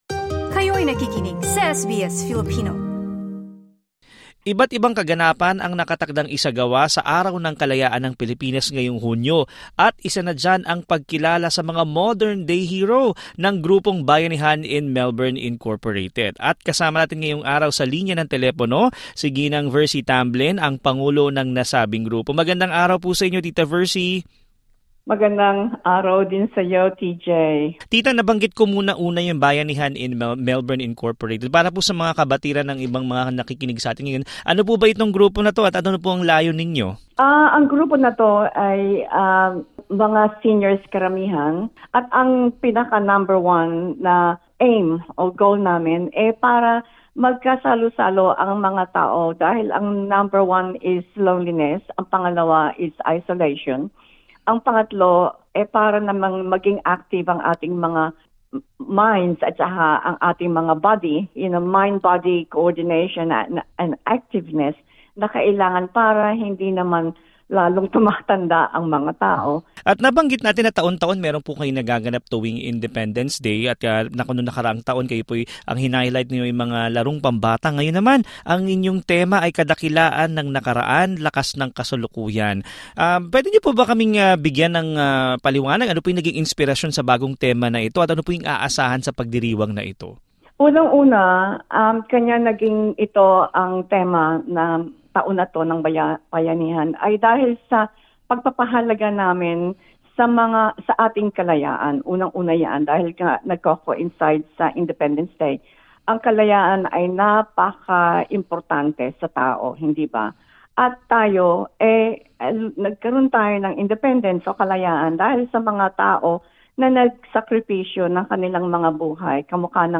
Sa isang panayam ng SBS Filipino